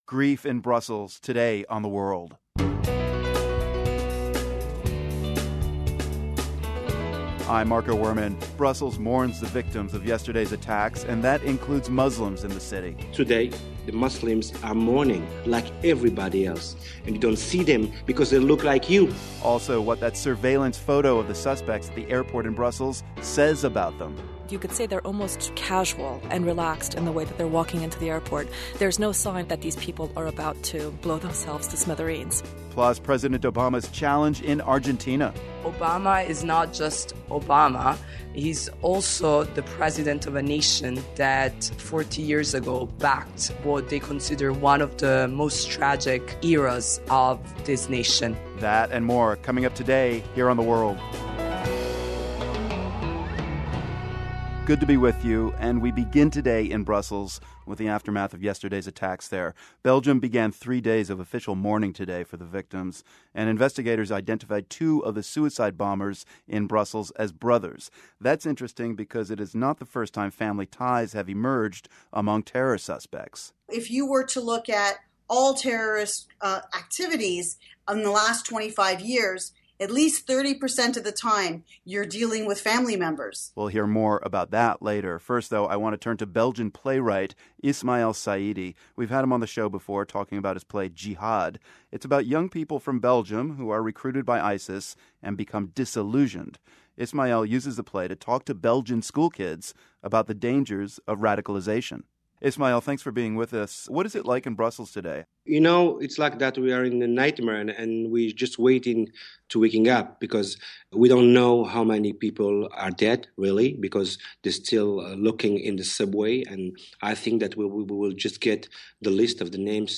speaks with a Belgian playwright